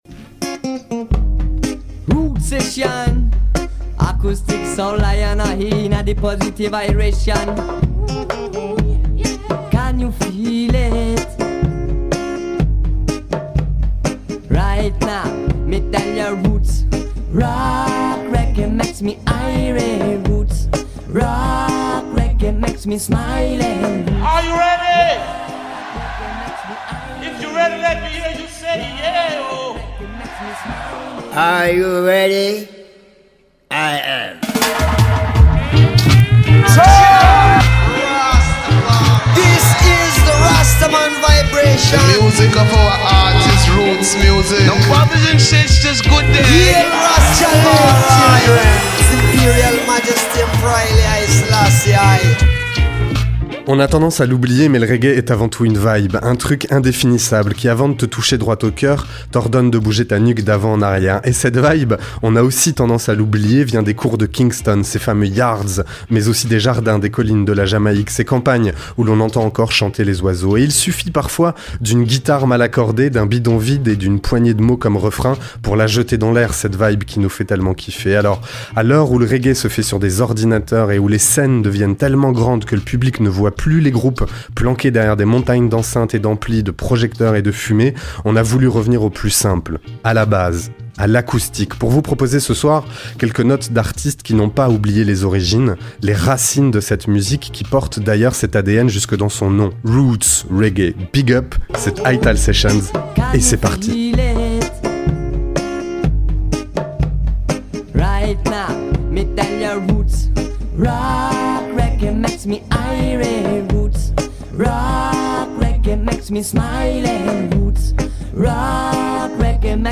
Acoustic Special